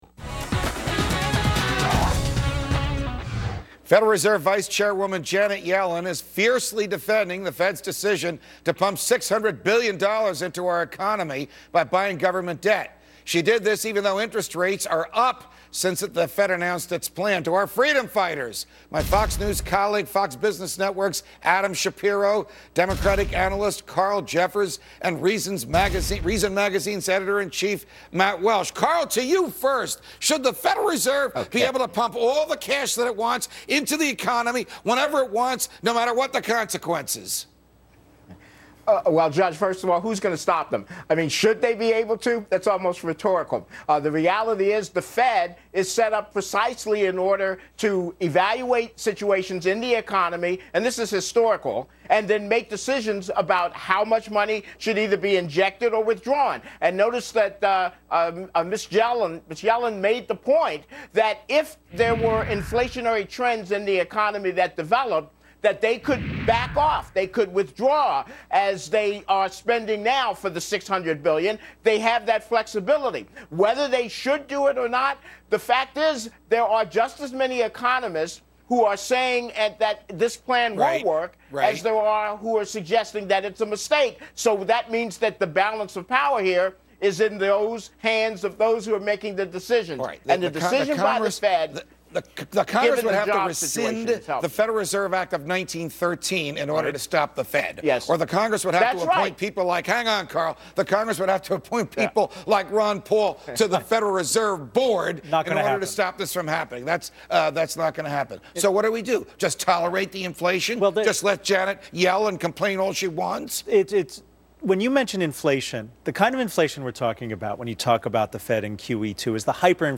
Reason Magazine Editor in Chief Matt Welch appeared on Freedom Watch With Judge Napolitano to talk about the potential inflationary effects of the Federal Reserve's second "Quantitative Easing" and whether or not the U.S. Department of Justice is working within its defined powers when it attempts to subpoena Twitter messages while building its case against Wikileaks.